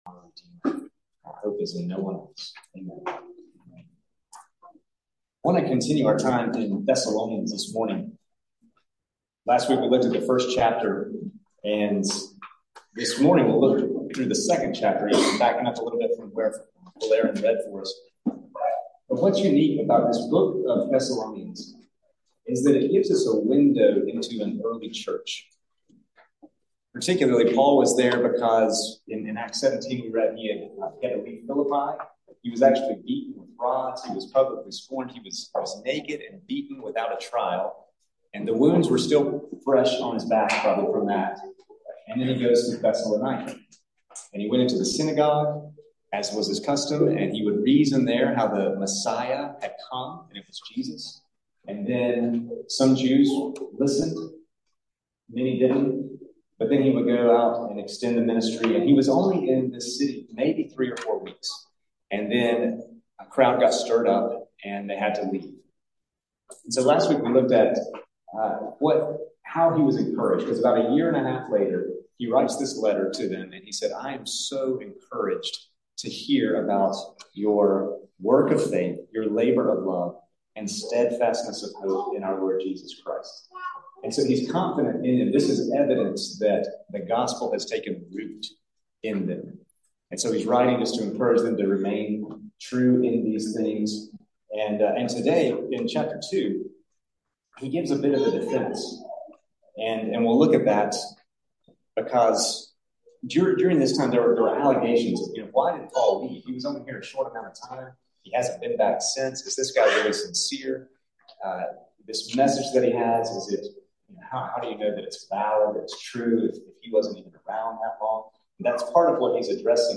Sermons | Christ the King